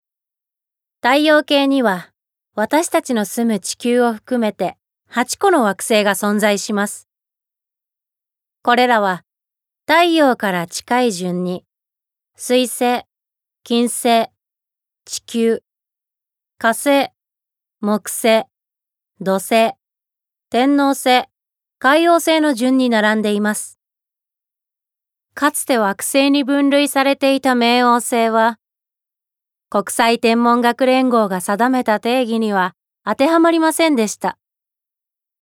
ボイスサンプル
ナレーション１